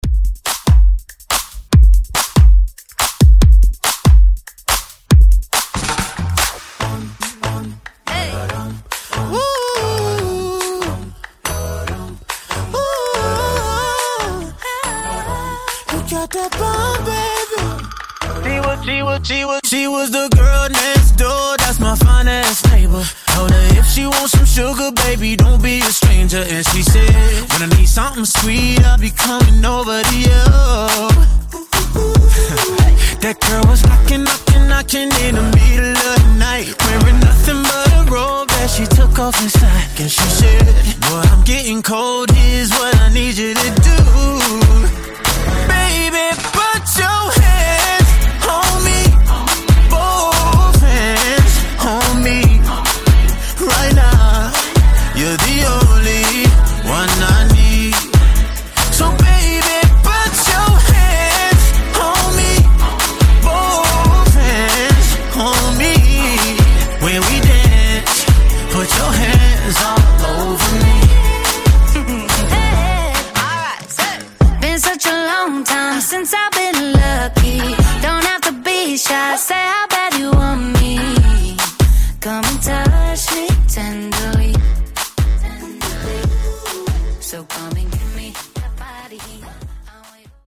Genres: DANCE , GERMAN MUSIC , RE-DRUM
Clean BPM: 126 Time